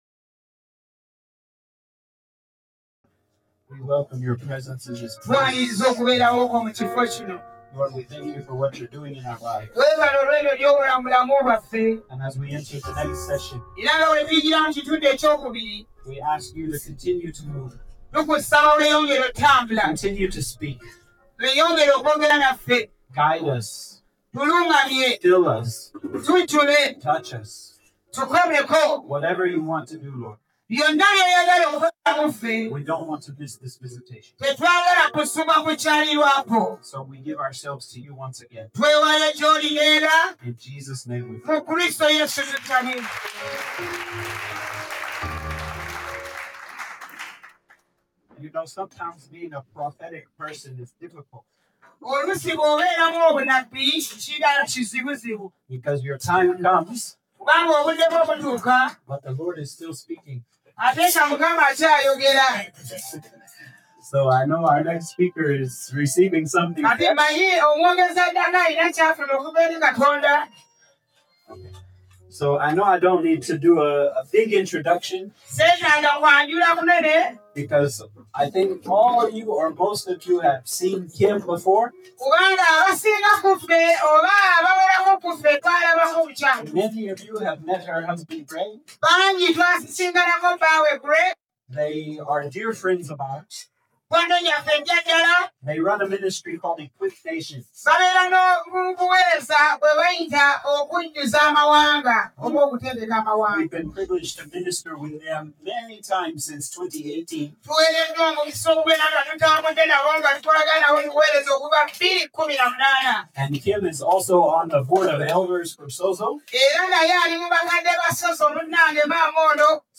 Sermons | Sozo Ministries